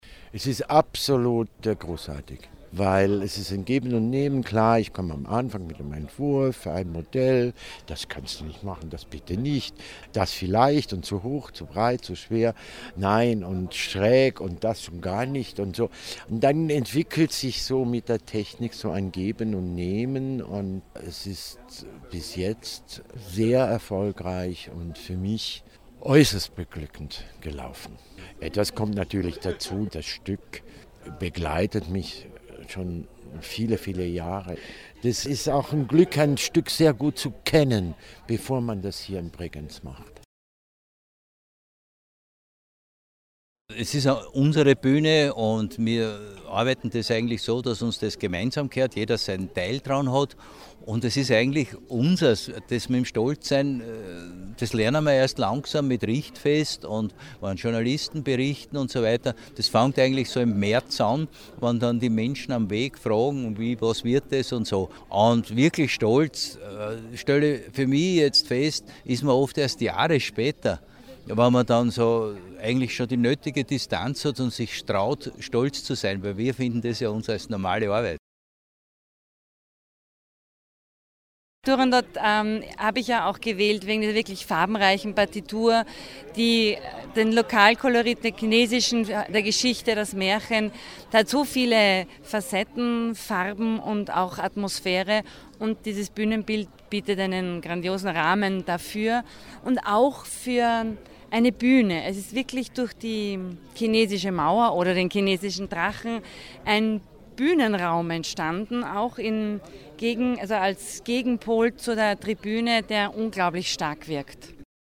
O-Töne "Turandot"-Richtfest - Beitrag